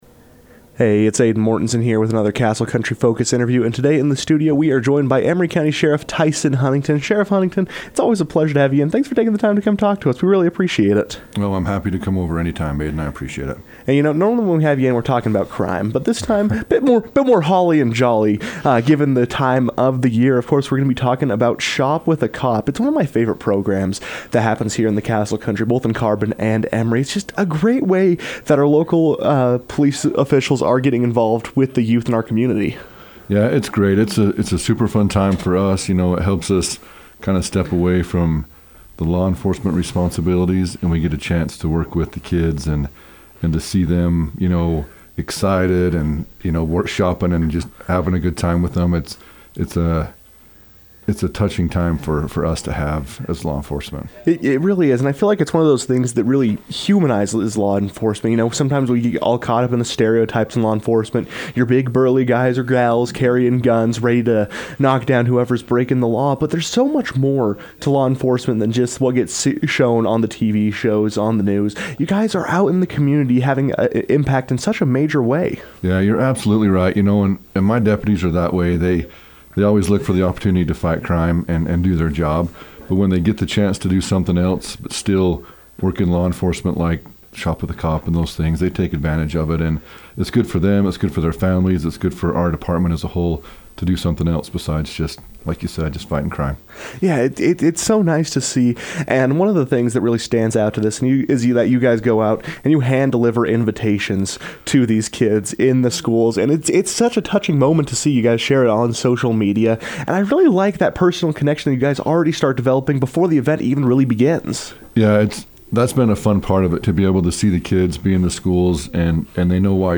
Emery County Sheriff Tyson Huntington discusses Shop with a Cop
Emery County Sheriff Tyson Huntington joined the KOAL newsroom to discuss the program and the excitement felt by all involved.